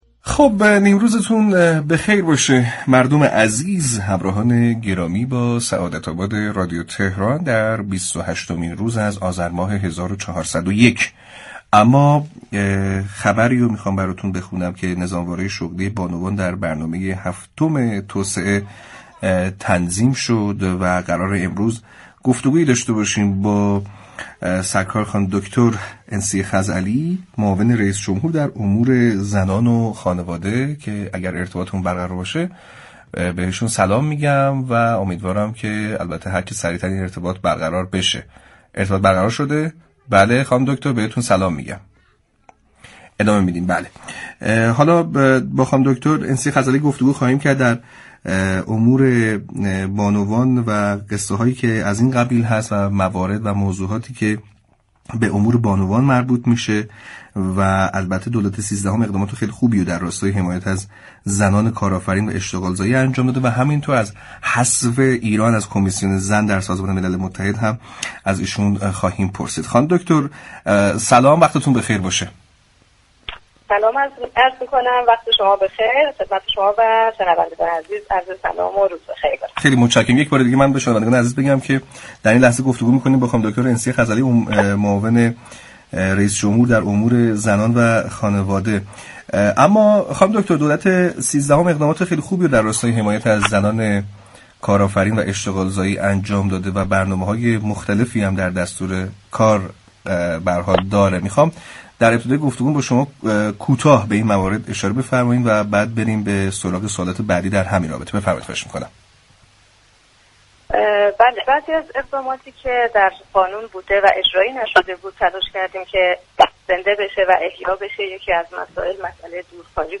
معاون رئیس جمهور در امور زنان و خانواده روز دوشنبه 28 آذر درباره طرح نظامواره شغلی زنان در برنامه هفتم توسعه، طرح های خانه‌های مولد و زنان كارآفرین و حذف ایران از كمیسیون مقام زن سازمان ملل گفت و گو كرد.